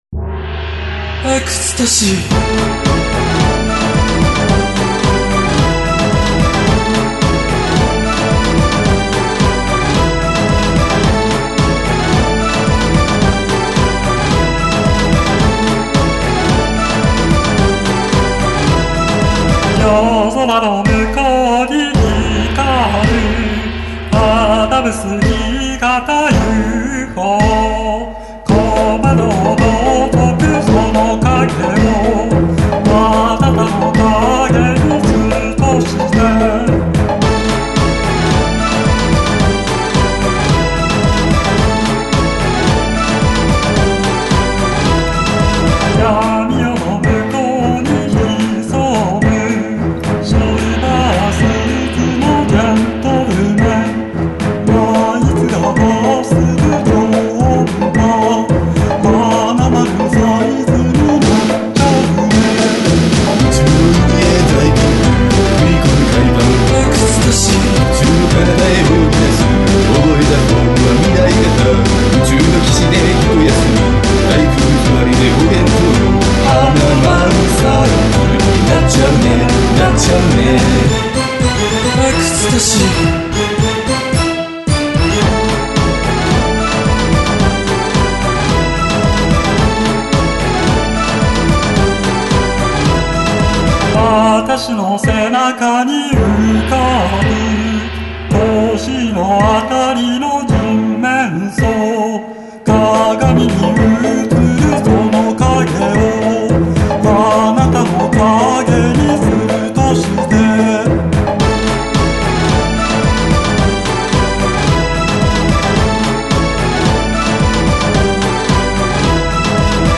2005年のリミックスバージョン（remix